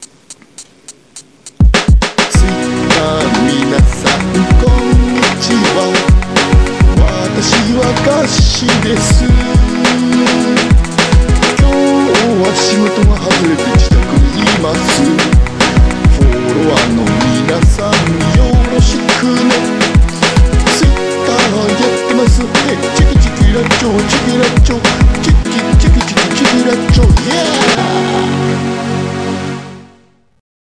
逆カラオケ。
テキトーに歌ったものに後から伴奏を付けてくれるiPhoneアプリ。